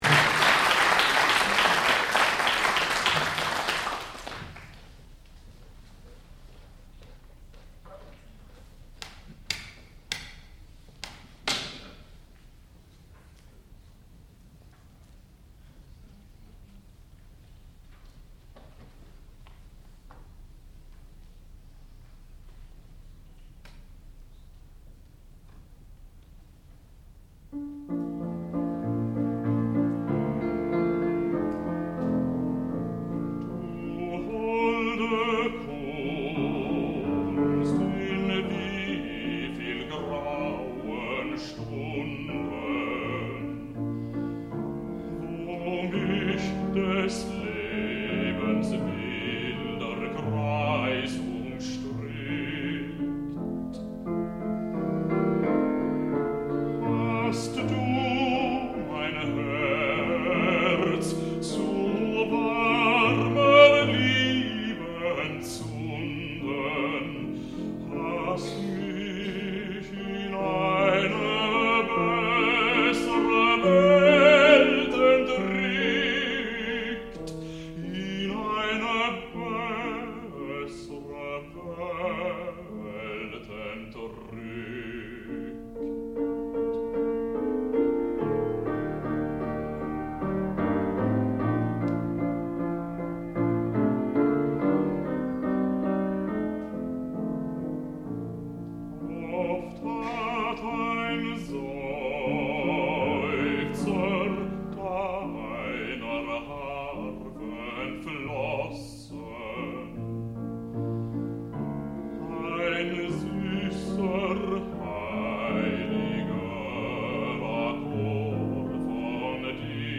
sound recording-musical
classical music
baritone
piano